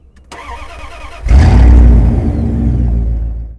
Index of /server/sound/vehicles/lwcars/quadbike
startup.wav